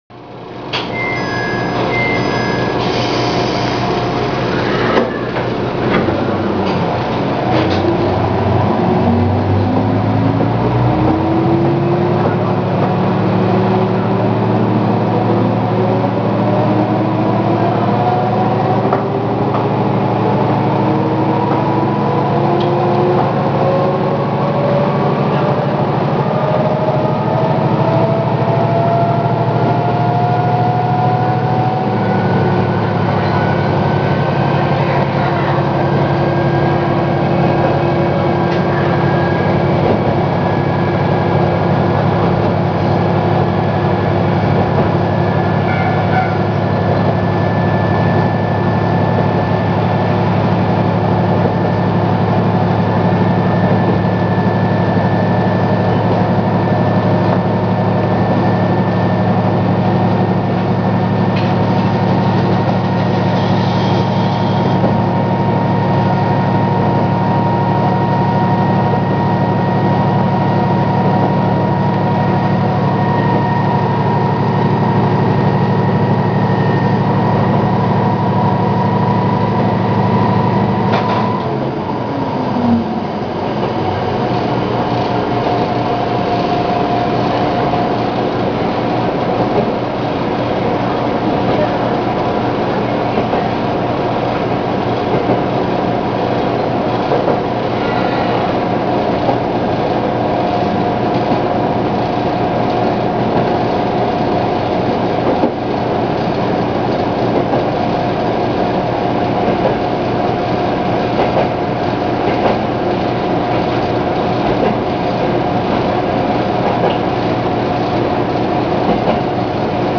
・MRT300形走行音
ドアチャイムは新型気動車にありがちな音。途中で単線から複線に変わるため、かなり減速しています。